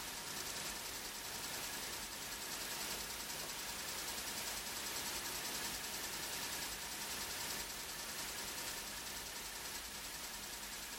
There are no significant frequency spikes with the fan spinning at 10% PWM speed.
I have recorded the signals shown above, but please keep in mind that I’ve enabled Automatic Gain Control (AGC) to do so to make it easier for you to reproduce them.
10% Fan Speed